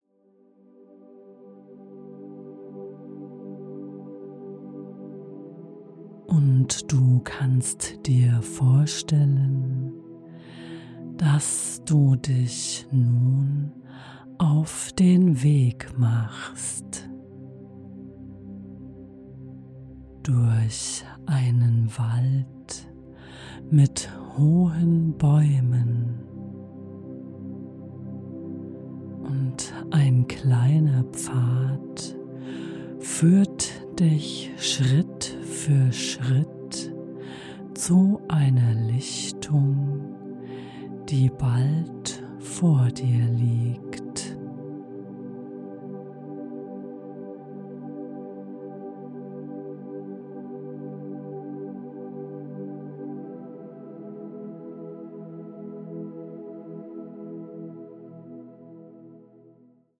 Sie ist leicht, beruhigend und wohltuend.